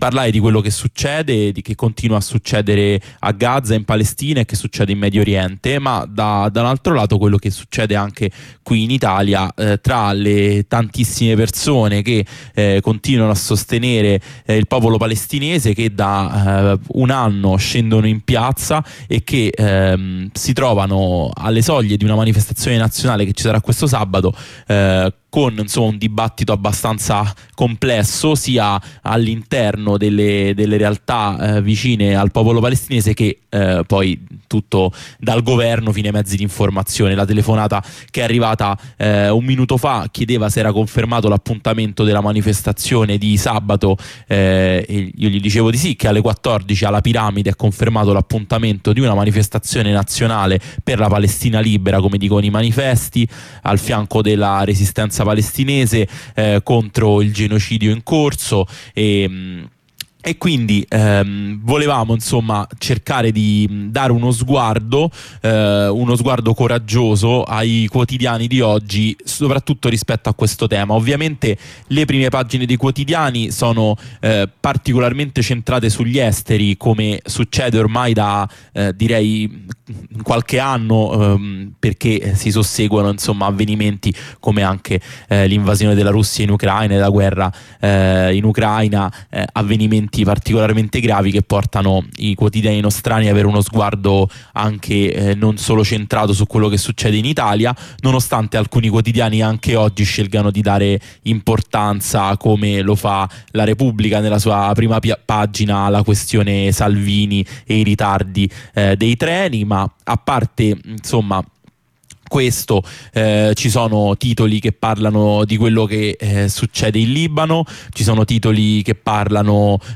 Rassegna stampa